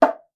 djembe2.wav